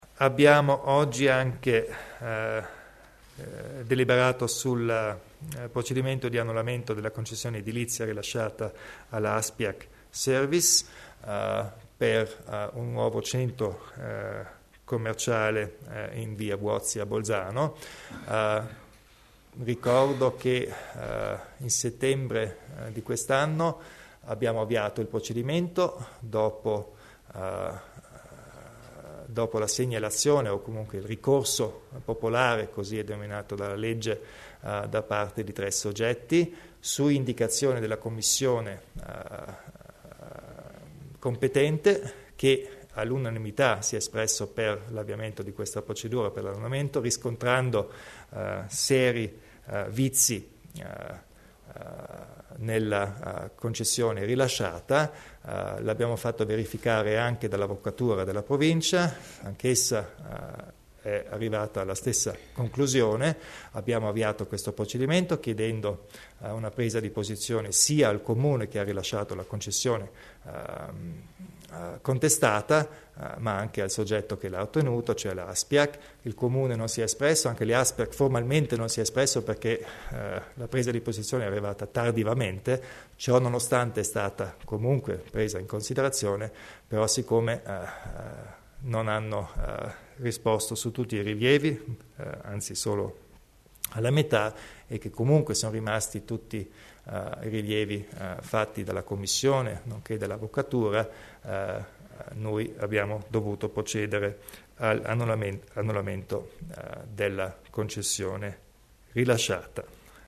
Il Presidente Kompatscher spiega le motivazioni per l'annullamento della concessione ASPIAG